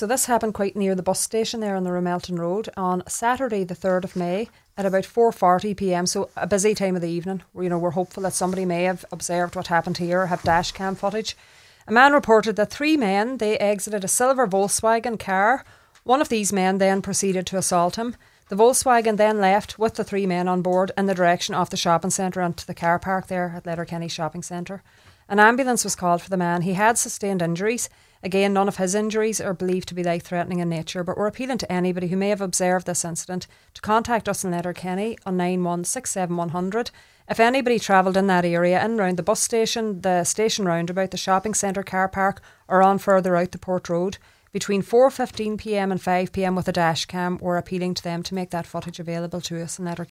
made the following appeal for information: